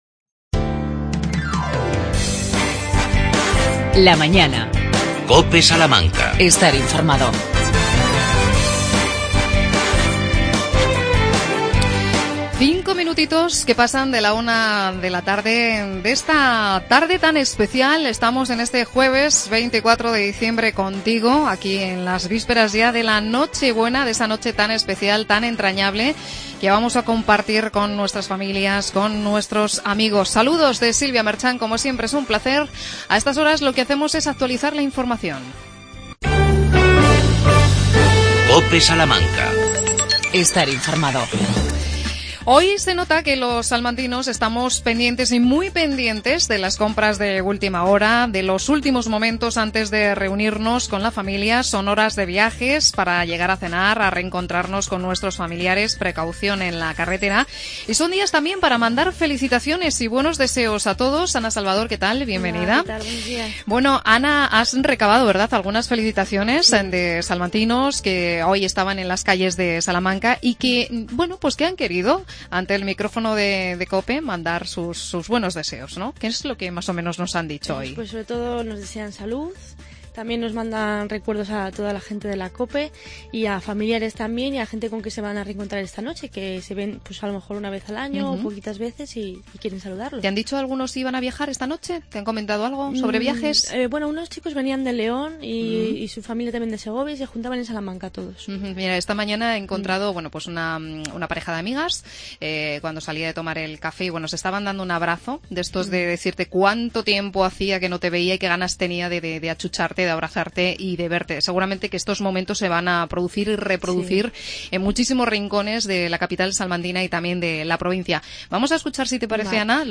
Paseamos por el Mercado Central